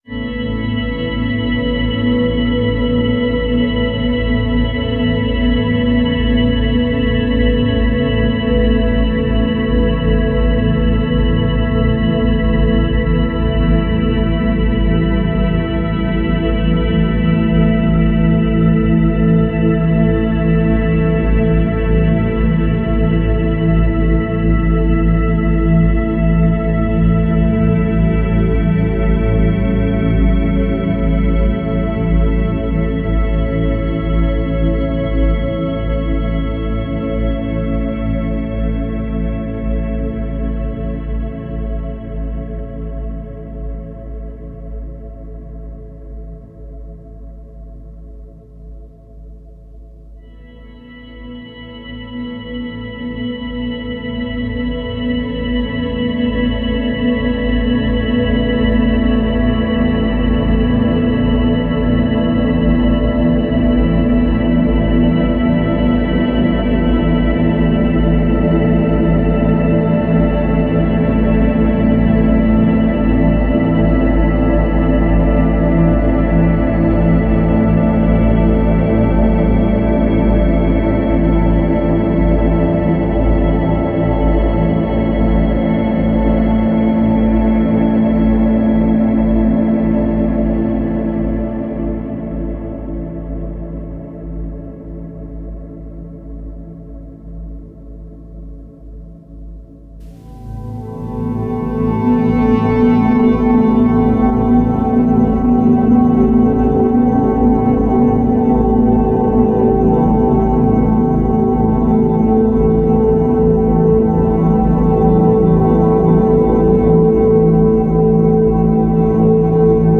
The sound of the church bells in Cortina d'Ampezzo striking nine o'clock, reimagined by Cities and Memory. Sounds constructed from the original field recording, October 2016.